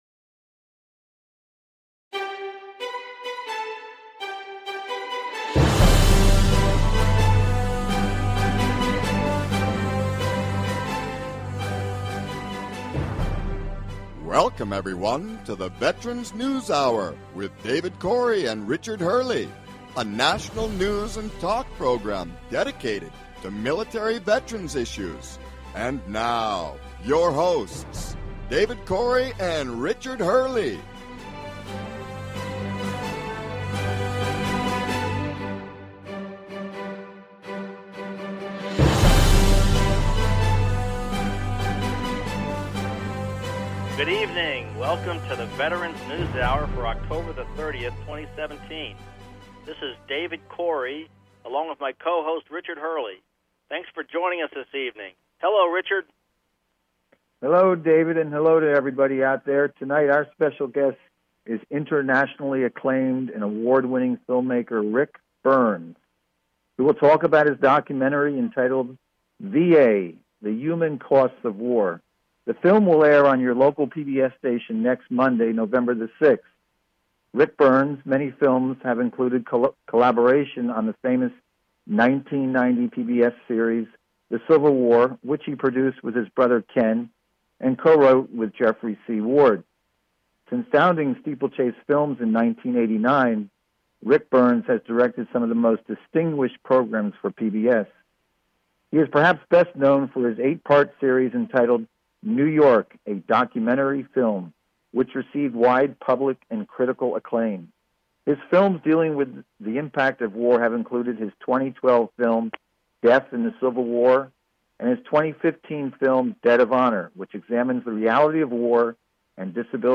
Talk Show Episode
Guest, Ric Burns - Internationally recognized documentary filmmaker and writer.